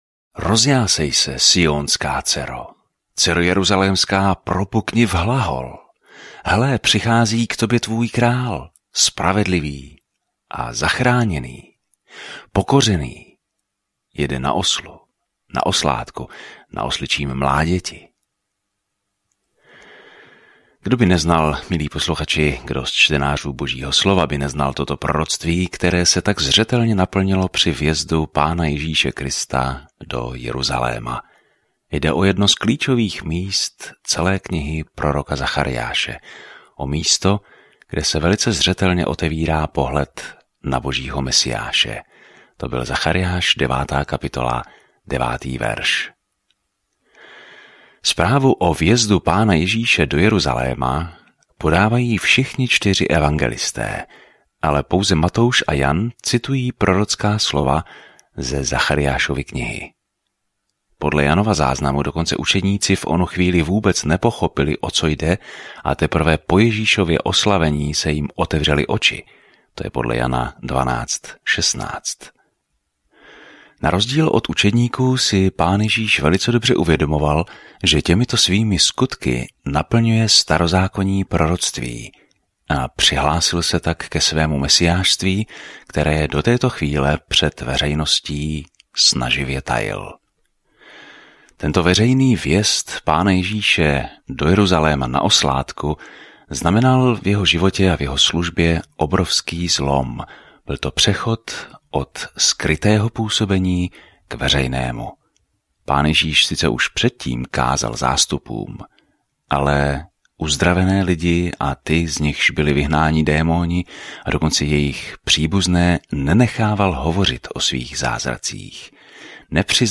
Písmo Zachariáš 9 Zachariáš 10:1-2 Den 18 Začít tento plán Den 20 O tomto plánu Prorok Zachariáš sdílí vize Božích zaslíbení, které lidem dají naději na budoucnost, a vybízí je, aby se vrátili k Bohu. Denně procházejte Zachariášem a poslouchejte audiostudii a čtěte vybrané verše z Božího slova.